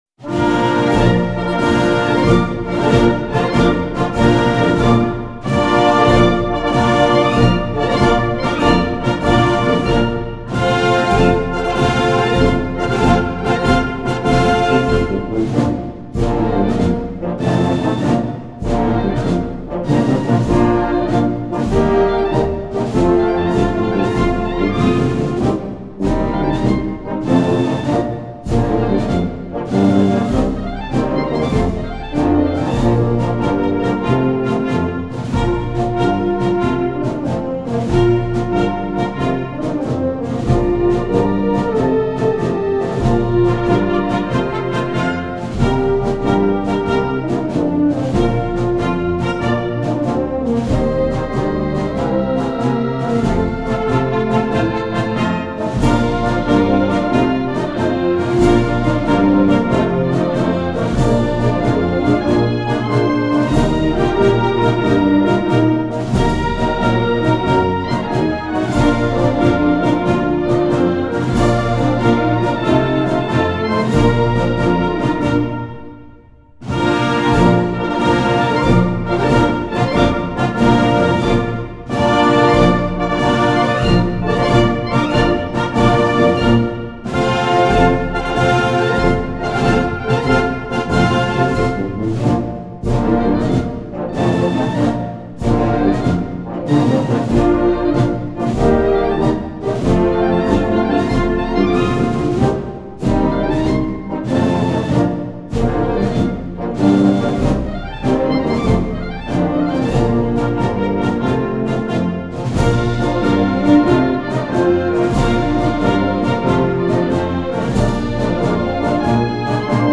Marchas de revista